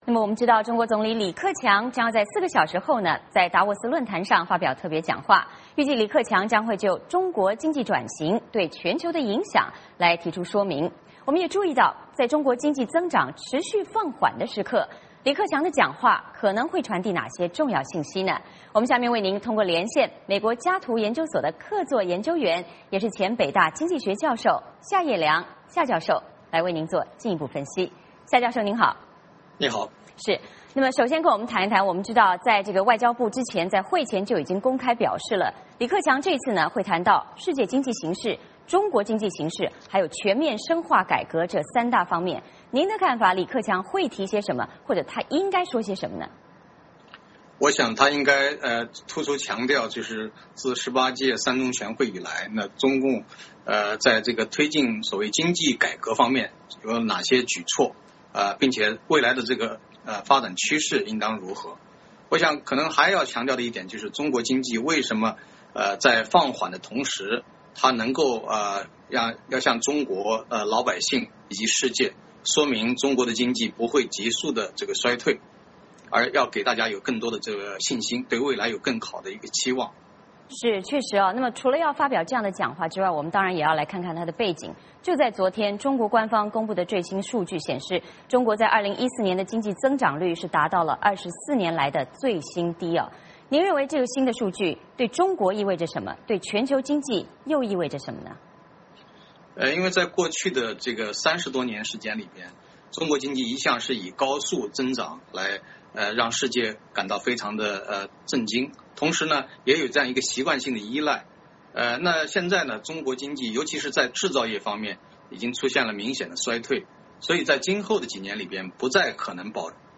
嵌入 VOA连线：中国声音随处可见，达沃斯已被中国包产？